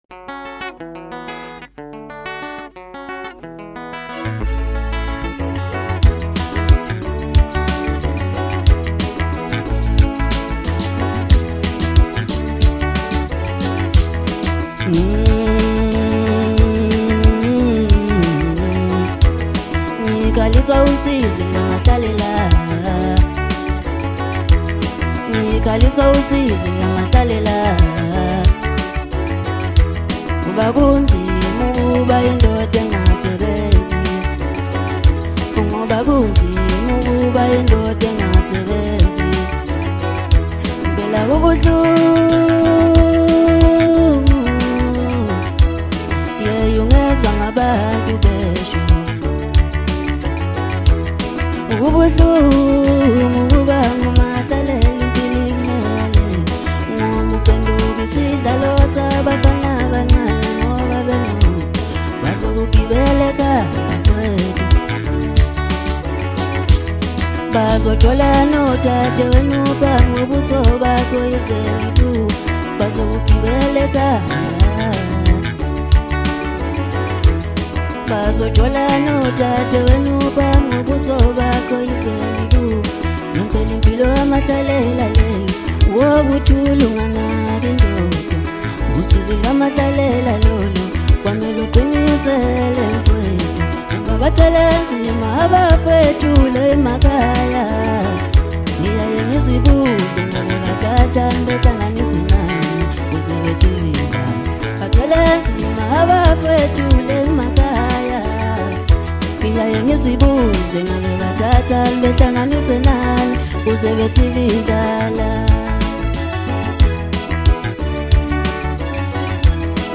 Home » Maskandi » DJ Mix